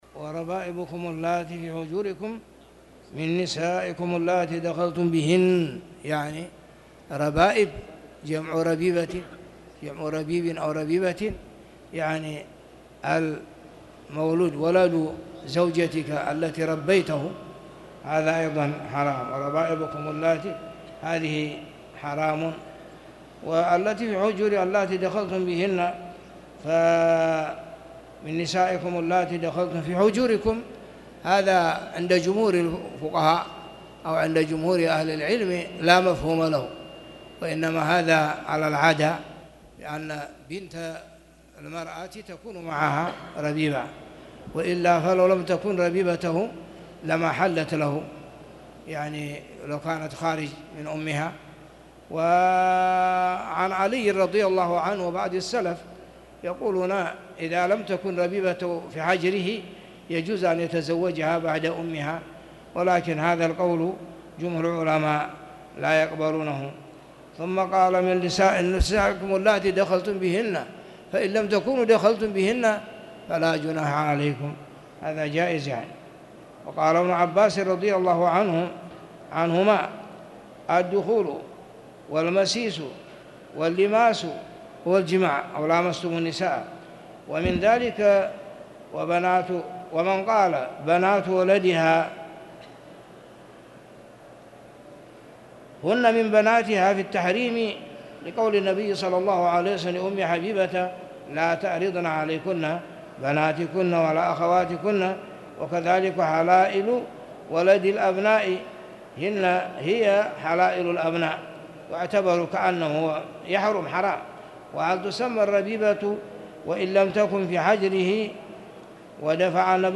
تاريخ النشر ١٩ شوال ١٤٣٧ هـ المكان: المسجد الحرام الشيخ